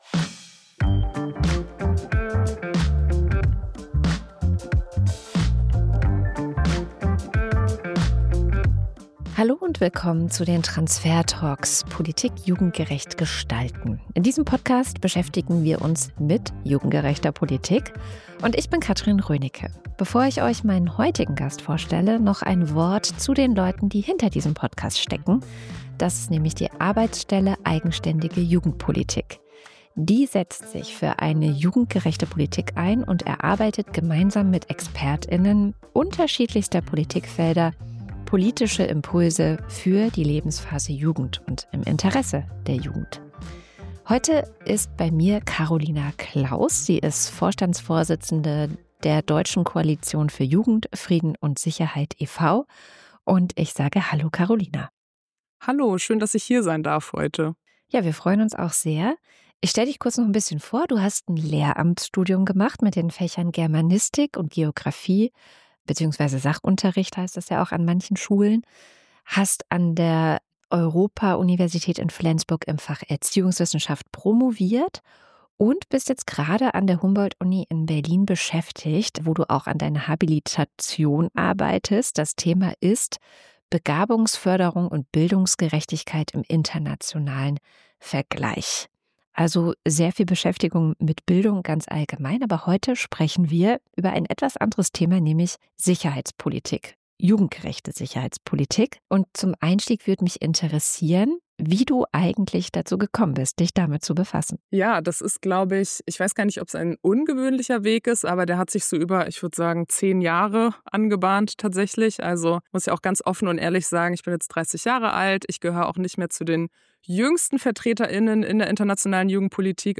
Es geht um Beteiligung, fehlende politische Strukturen, die Kraft ehrenamtlichen Engagements und die Frage, was es braucht, damit Jugend wirklich mitentscheiden kann. Eine spannendes Gespräch über Verantwortung, Erfahrungsräume und darüber, wie Frieden überhaupt verhandelt wird.